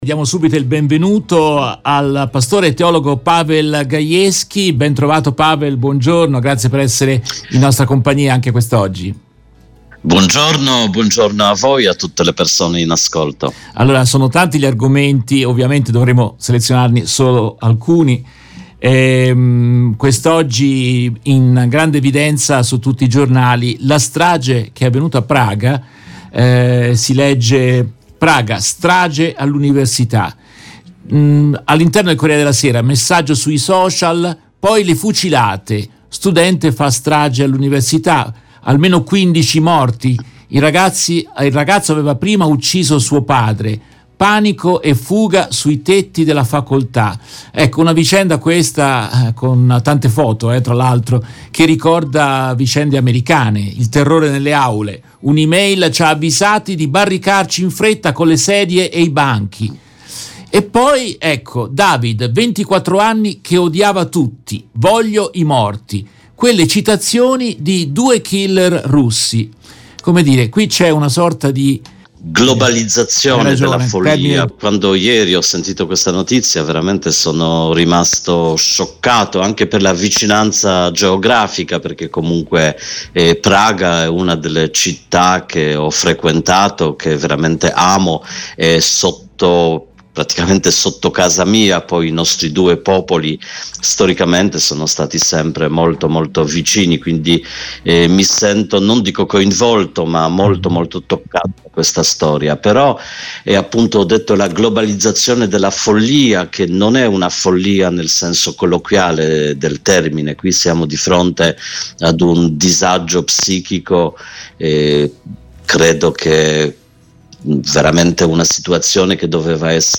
In questa intervista tratta dalla diretta RVS del 22 dicembre 2023